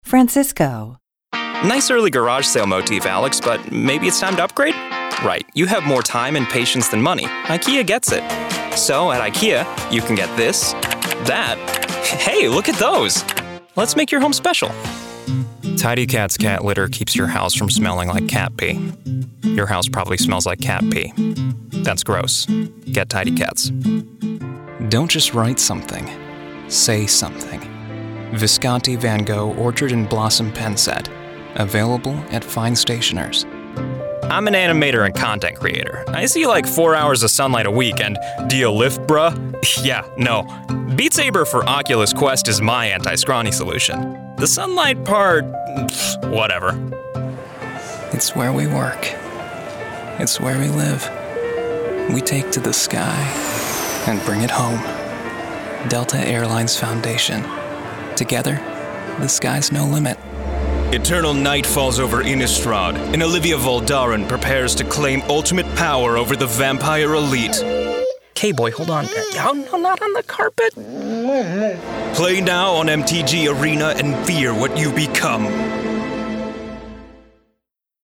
Showcase Demo (English)
announcer, anti-announcer, classy, confident, conversational, cool, father, friendly, genuine, Gravitas, humorous, millennial, nostalgic, professional, promo, sincere, sophisticated, thoughtful, upbeat, warm